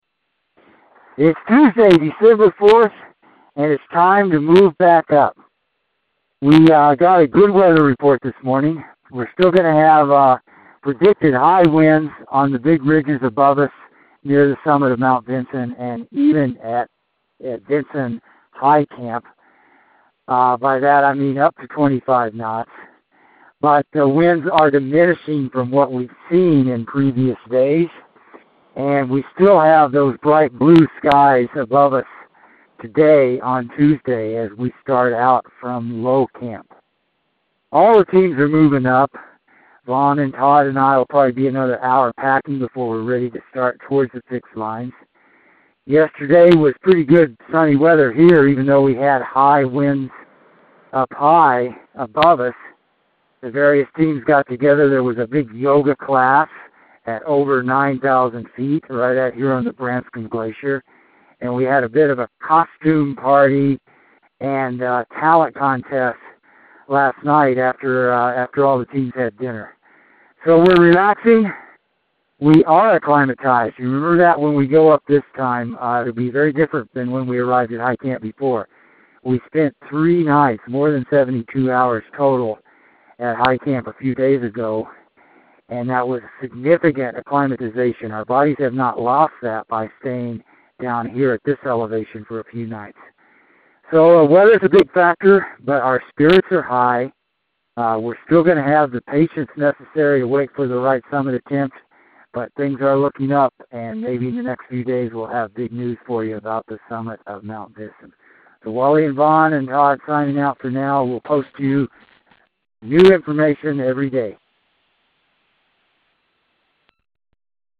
Expedition Dispatch